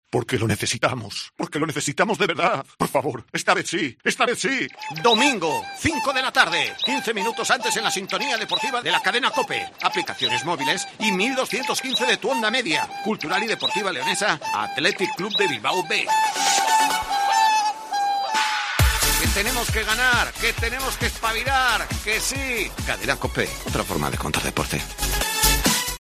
Escucha la cuña promocional del partido Cultural-Atletic Bilbao B el día 12-09-21 a las 17:00 h en el 1.215 OM